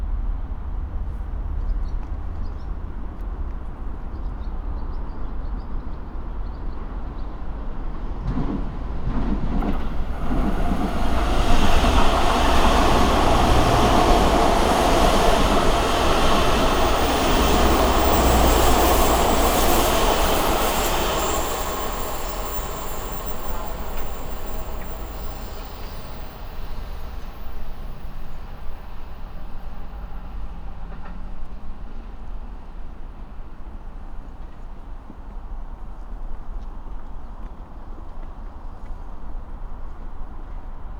RAMSA WM-8160 無指向性マイクの間隔は約2メートル。
上り電車通過。
H5studio 外部マイク RAMSA WM-8160＋
Rycote 5cm Classic-Softie (24/25) Windshield